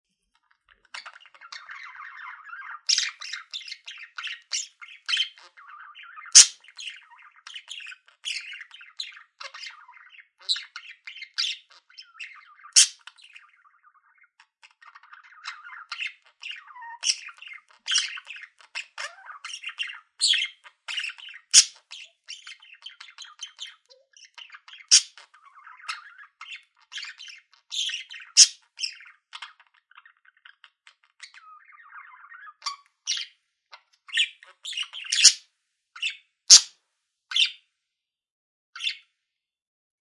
Periquito australiano (Melopsittacus undulatus)